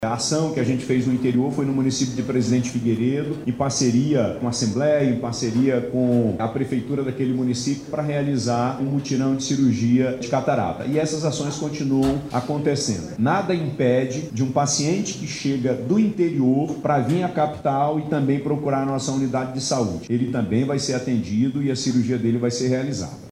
Durante o anúncio, nesta segunda-feira 20/10, no Centro de Convenções Vasco Vasques, o governador Wilson Lima, explicou que os atendimentos também se estendem à pacientes vindos os interior do Estado.